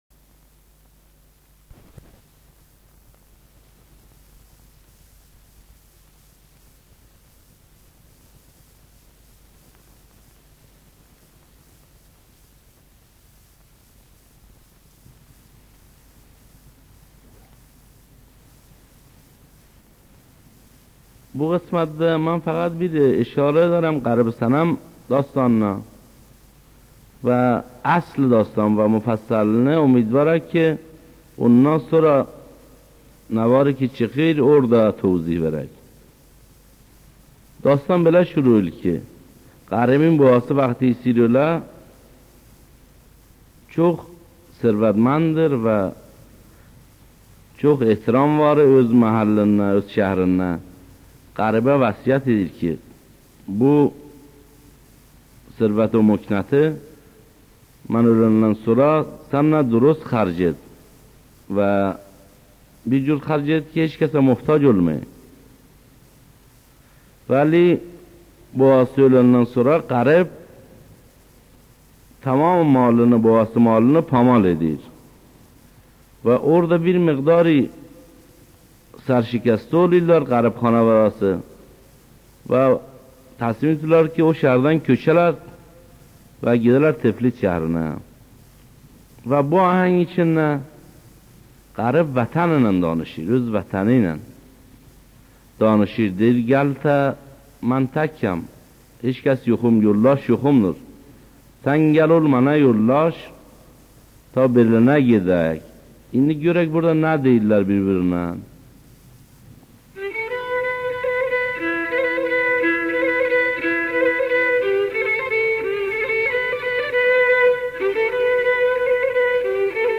داستان فولکلوریک